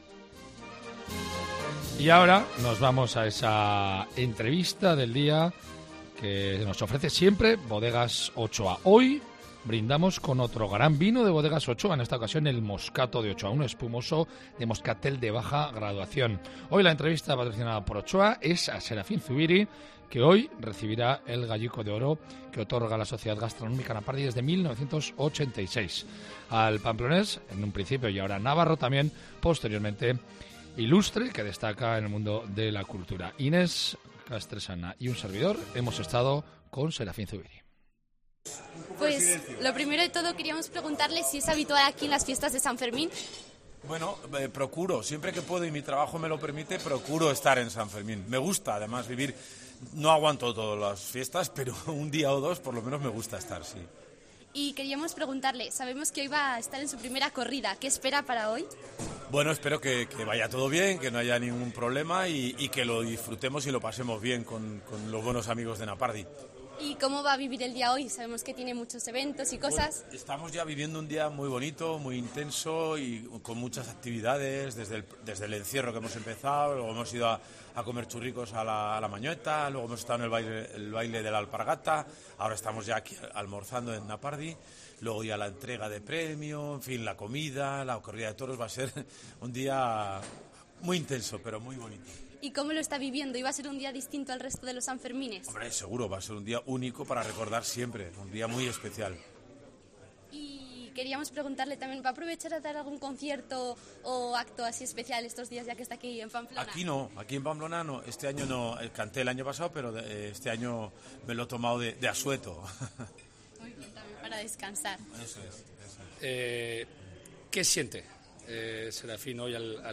la entrevista del día de Bodegas Ochoa